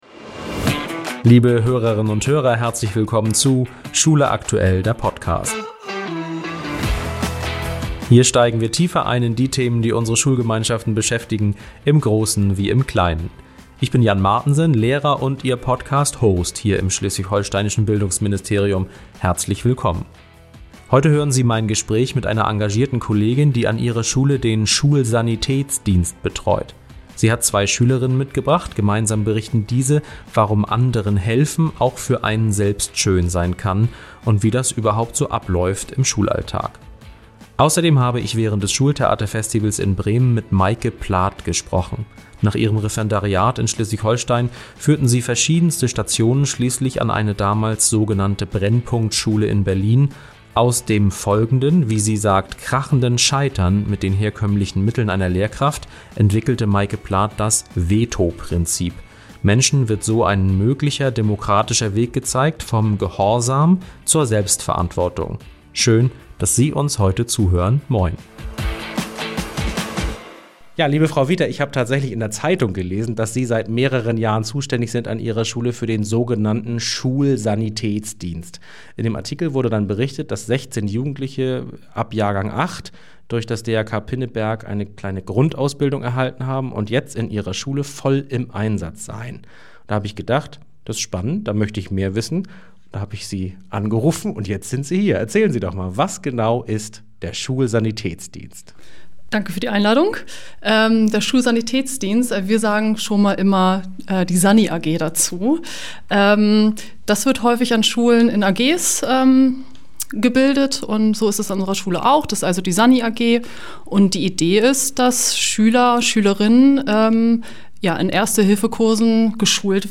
Wenn man anderen hilft, sagen zwei Schülerinnen und ihre Lehrerin im Interview über den erfolgreichen Schulsanitätsdienst an ihrer Schule, geht es einem selbst auch besser.